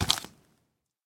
Minecraft Version Minecraft Version snapshot Latest Release | Latest Snapshot snapshot / assets / minecraft / sounds / mob / zombie / step3.ogg Compare With Compare With Latest Release | Latest Snapshot
step3.ogg